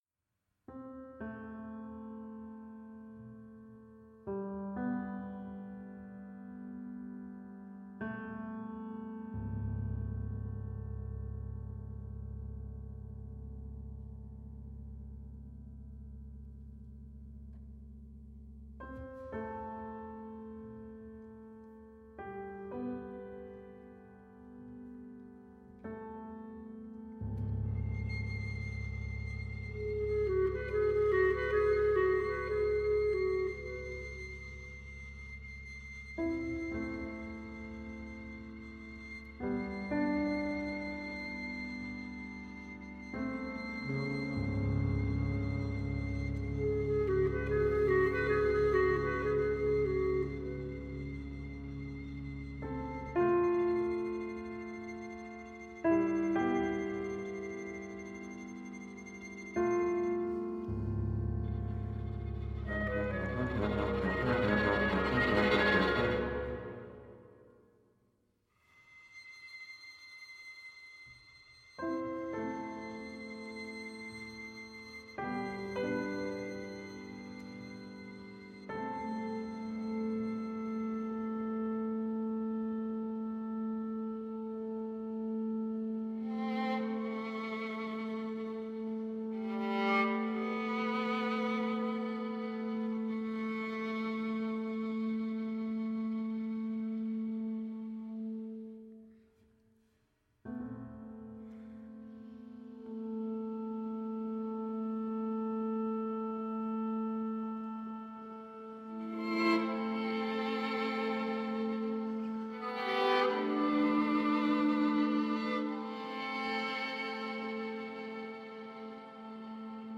ポーランドの現代音楽アンサンブル、待望の第２弾